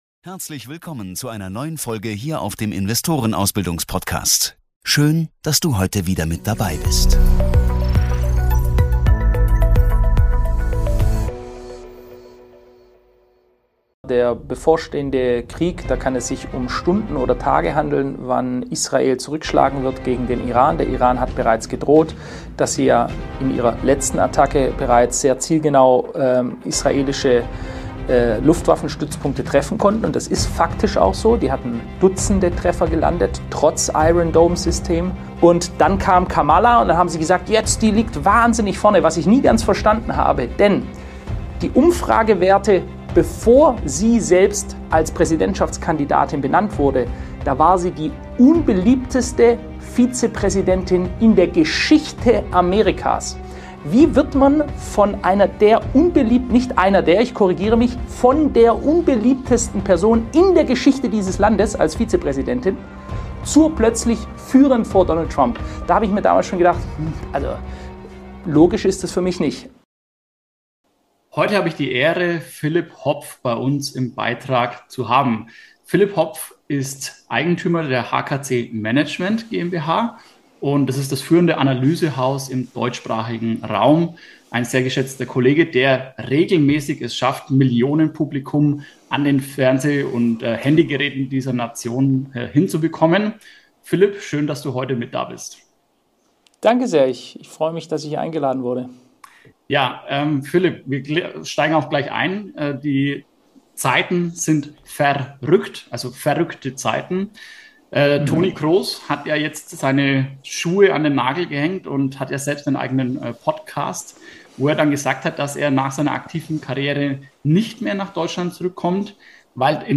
In diesem explosiven Gespräch